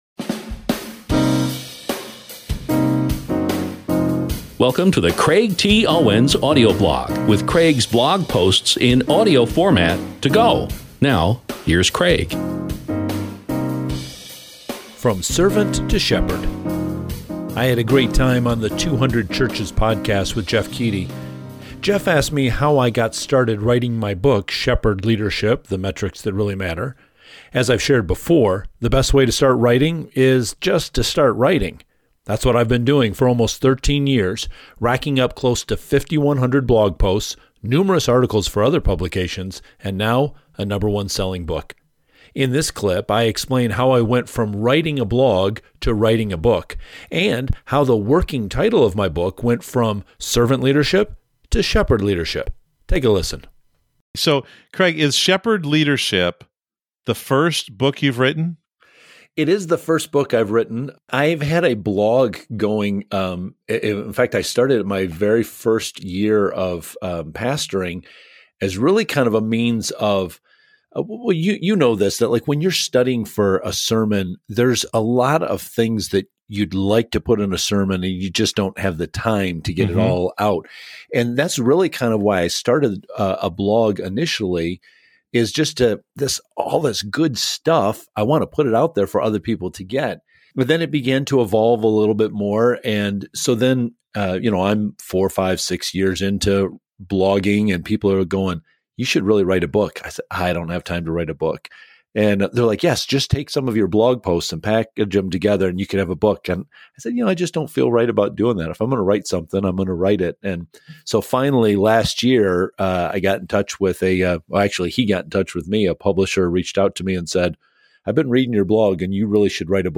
I’ll be sharing more clips from this interview soon, so please stay tuned.